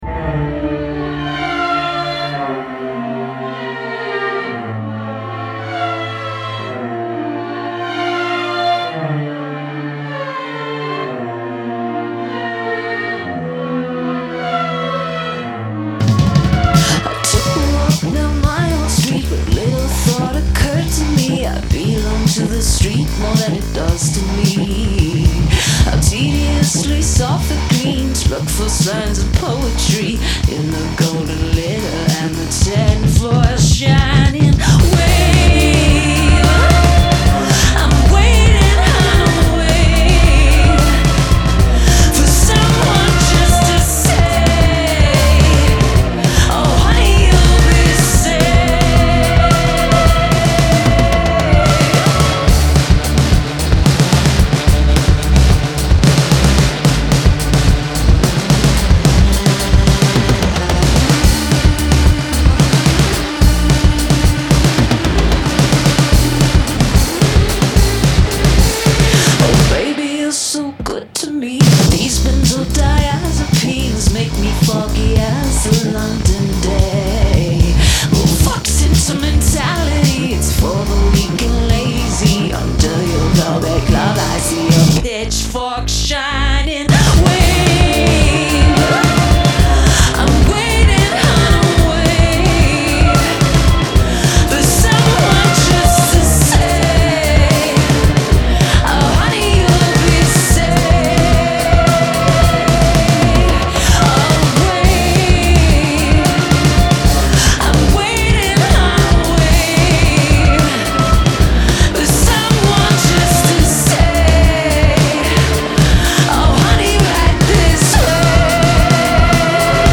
Genre : Bandes originales de films